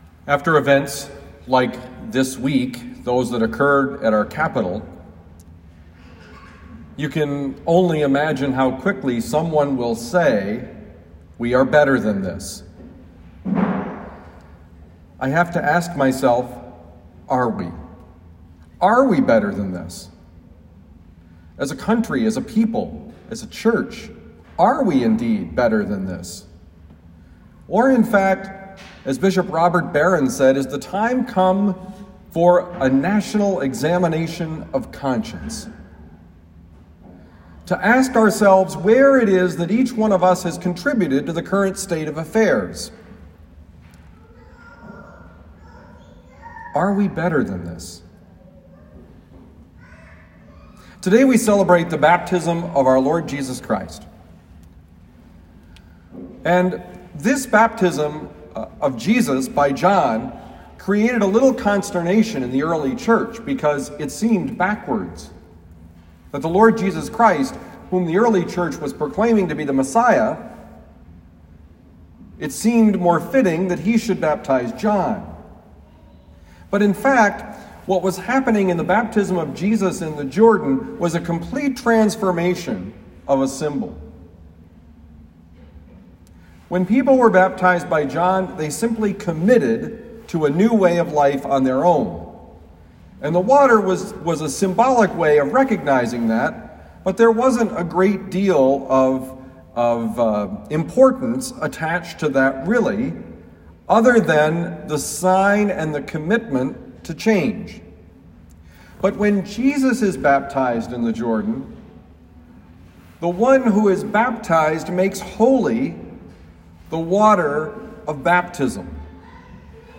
Homily for January 10, 2021
Given at Our Lady of Lourdes Parish, University City, Missouri.